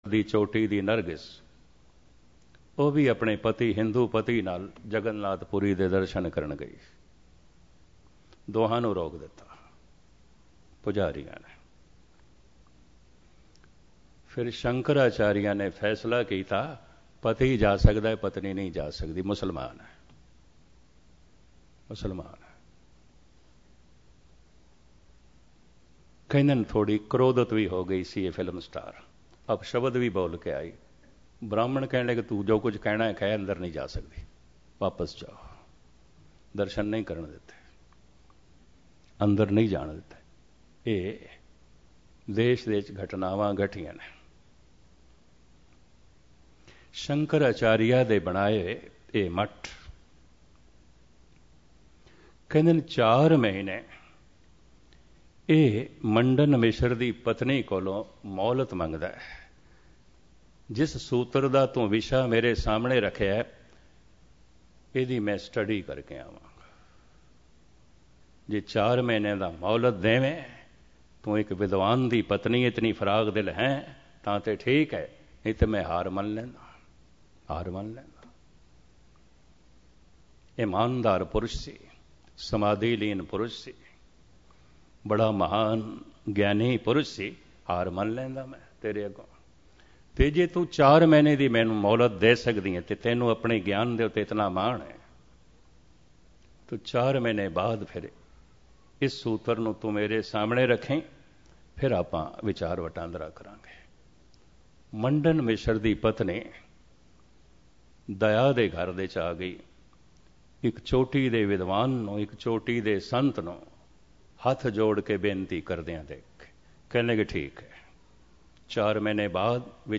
Katha
Album: SookshamSreer Genre: Gurmat Vichar